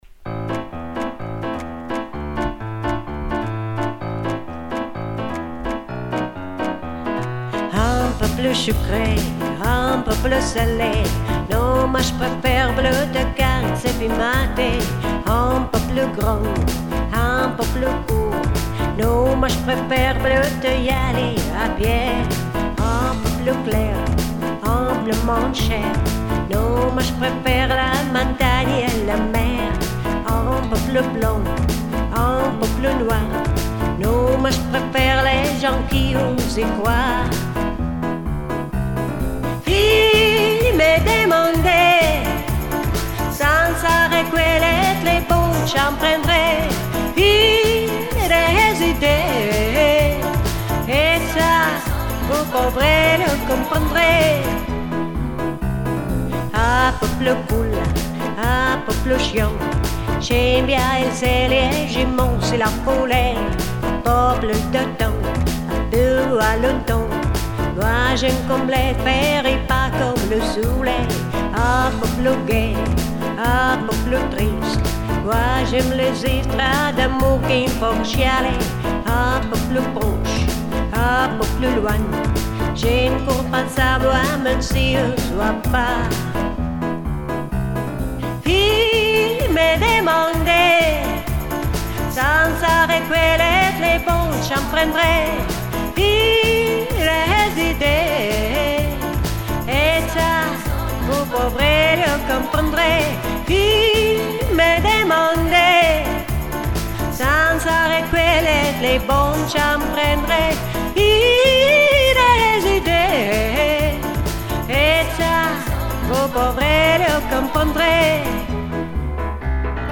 Французский стиль схвачен в точку! Тембр соответствует.
Это же твоя фирменная фишечка - френч шансон)))